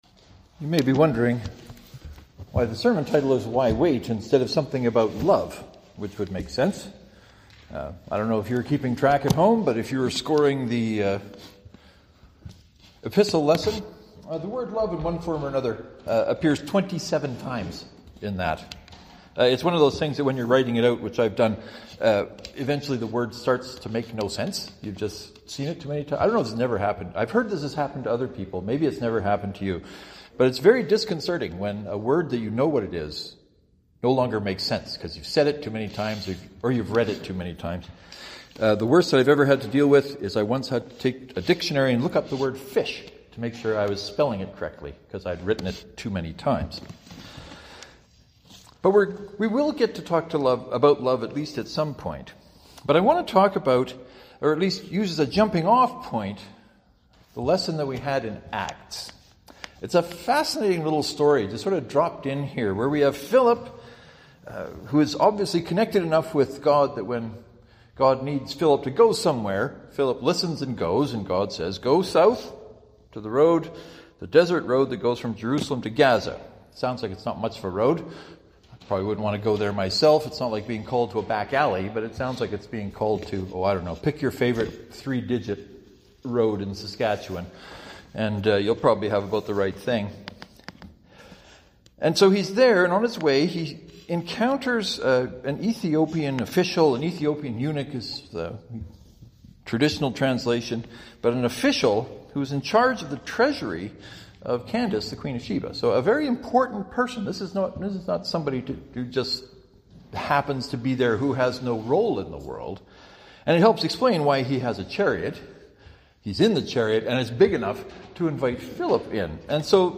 As always, I am delighted to hear what you think about the sermon so please let me know as you are inclined and able.